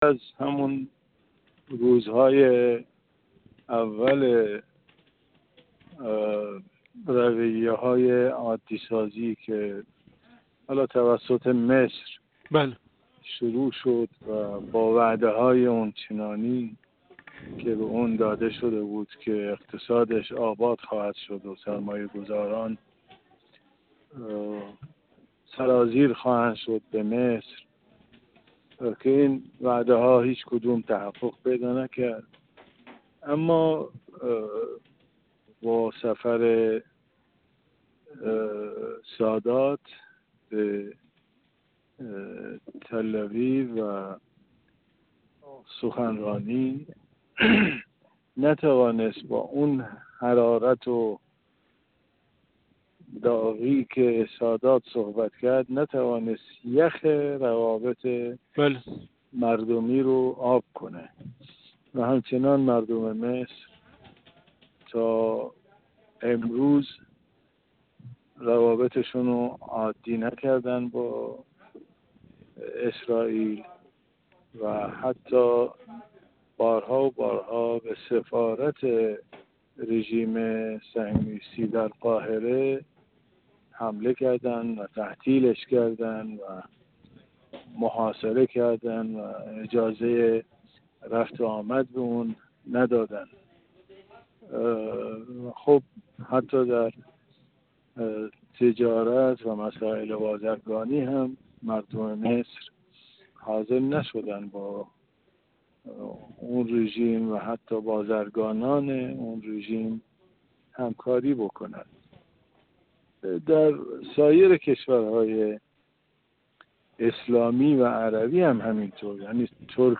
کارشناس مسائل منطقه